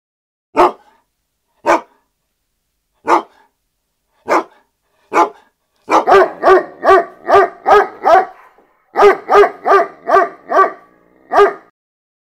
Normal Dog Barking Sound Effect Free Download
Normal Dog Barking